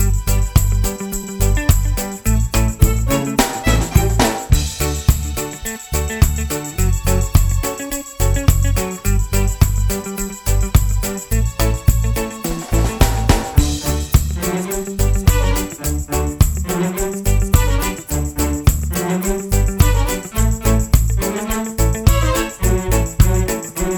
no Backing Vocals Reggae 3:16 Buy £1.50